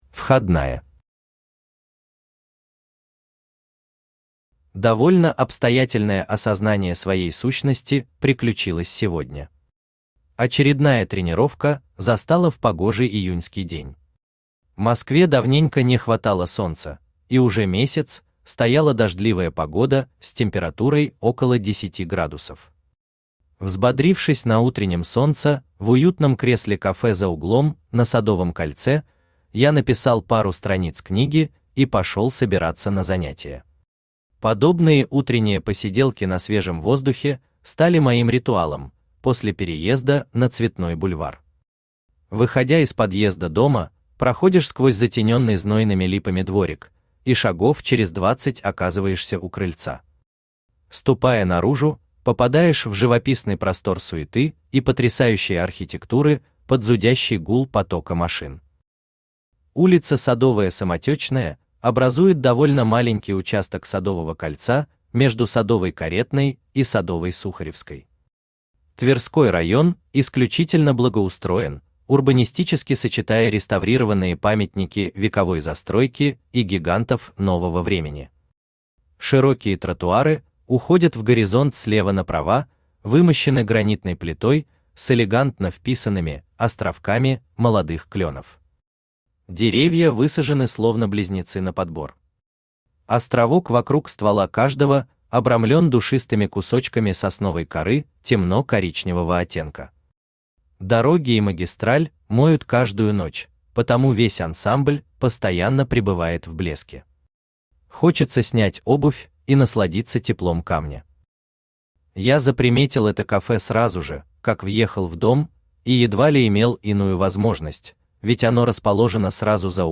Аудиокнига Книга пятая. Реформы тела | Библиотека аудиокниг
Реформы тела Автор Александр Алексеевич Ананьев Читает аудиокнигу Авточтец ЛитРес.